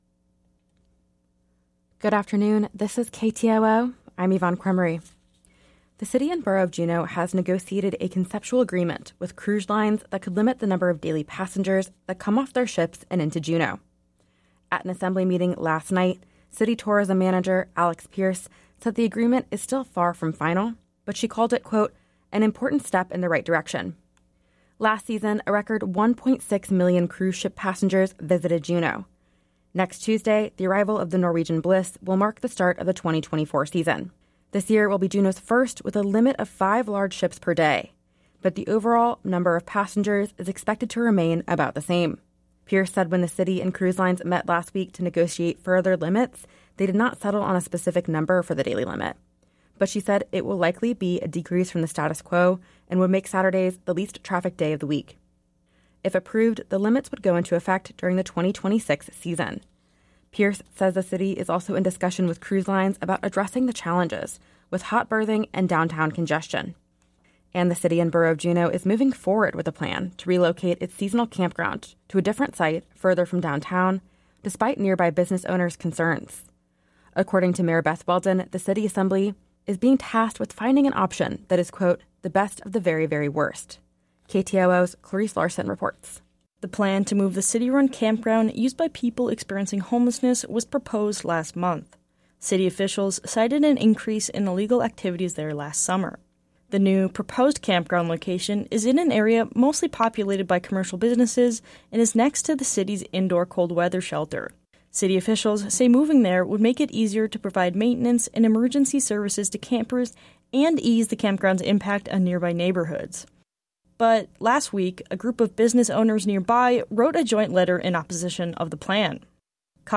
Newscast – Tuesday, April 2, 2024